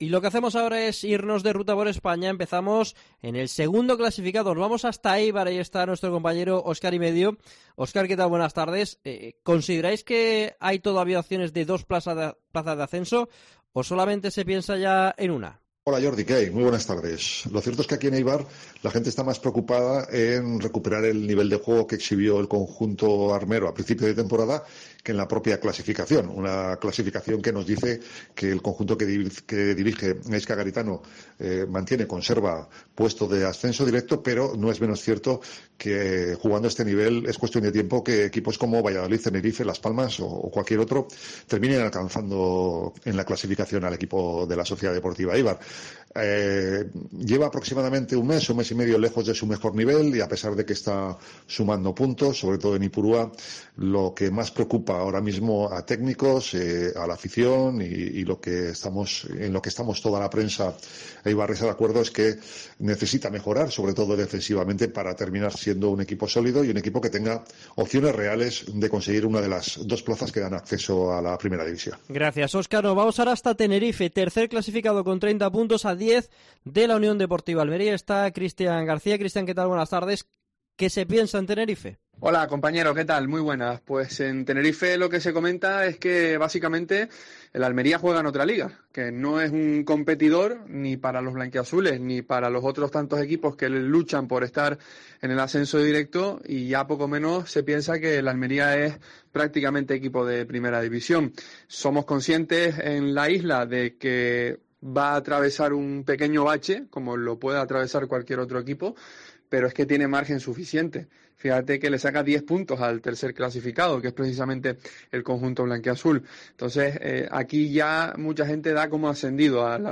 En Deportes COPE Almería hemos ido de ' ruta por la Liga SmartBank ', para conocer la opinión de los narradores de Tiempo de Juego que cubren habitualmente a los rivales directos de la UDA.